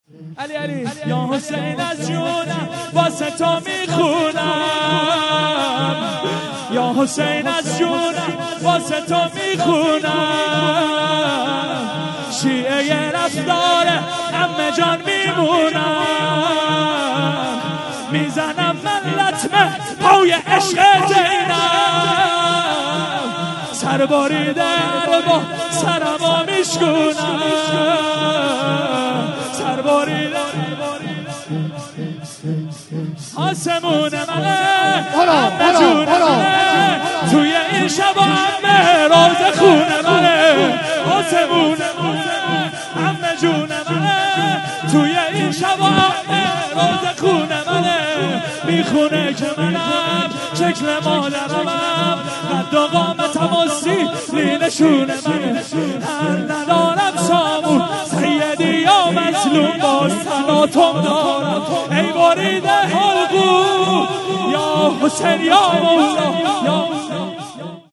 5- یا حسین از جونم واسه تو میخونم - شور